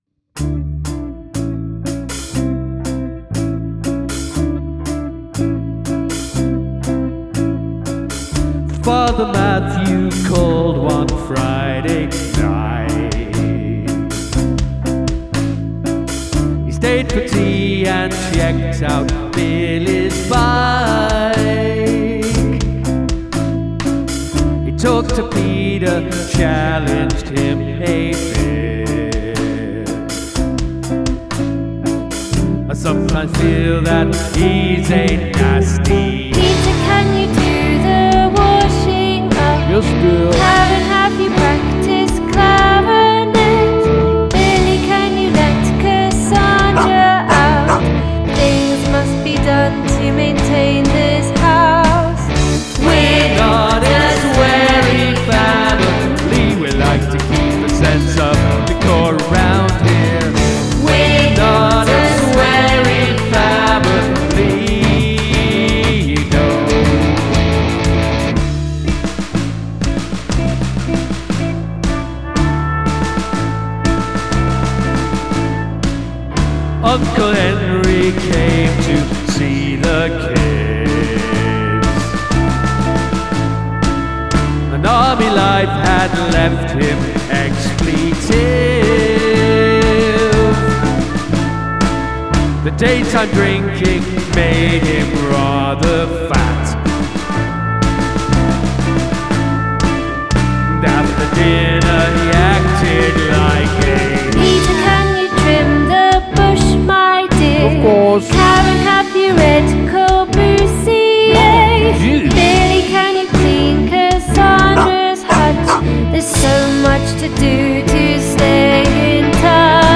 The cut in the middle of "family" is the cherry on top of this funny and clever song.
The vocal production is fantastic.
The dog barks, drums everything, oh my goodness!!